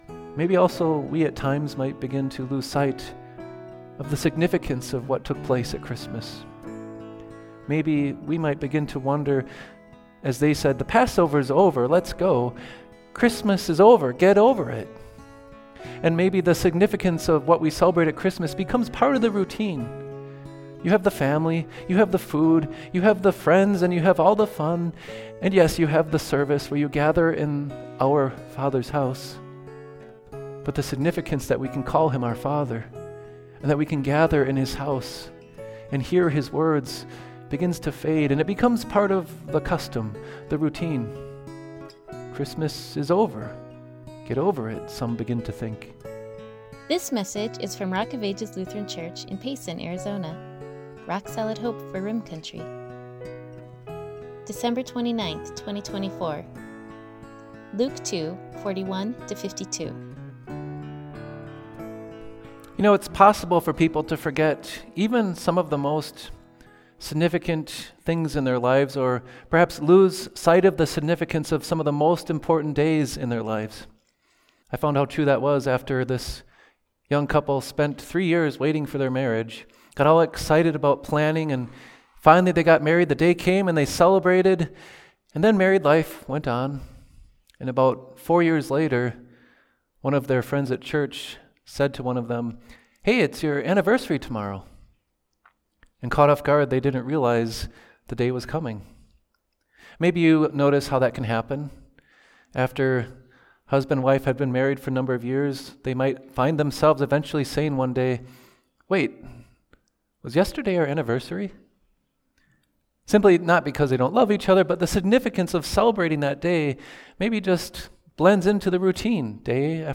The Gift of God 3) Perfect Substitute & Sacrifice Sermons Luke 2:41-52 ● 2024-12-29 ● Christmas Series ● Listen Podcast: Play in new window | Download Subscribe: Apple Podcasts | Spotify | Pandora | RSS